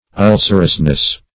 Ul"cer*ous*ness, n.